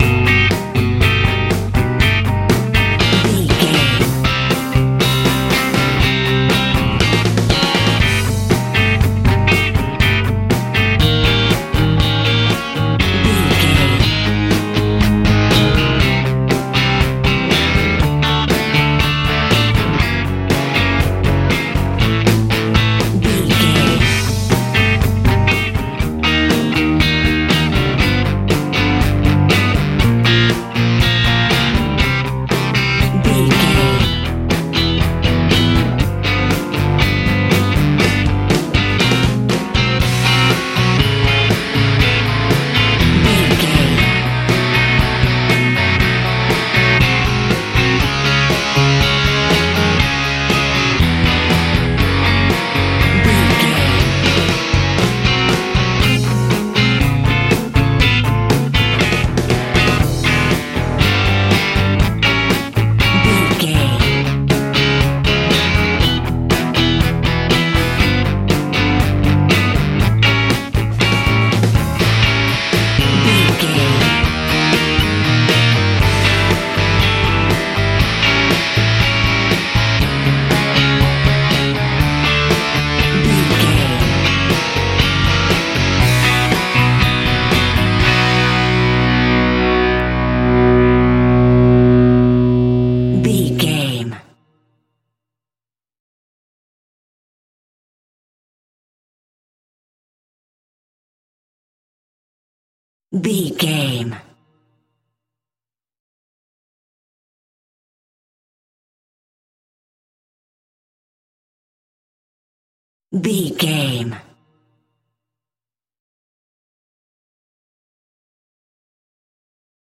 Ionian/Major
groovy
funky
bouncy
driving
energetic